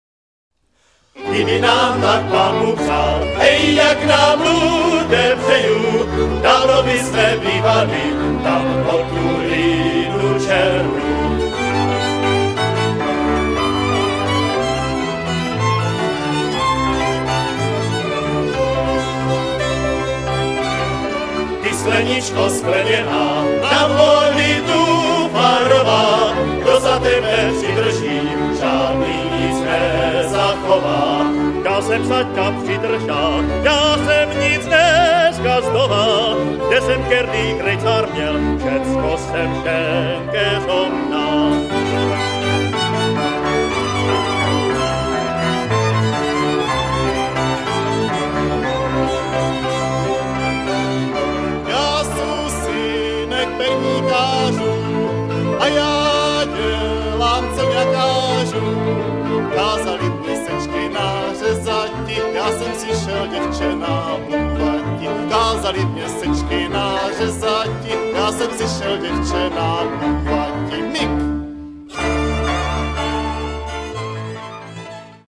Písničky z Luhačovického zálesí a Valašska v podání souboru Májek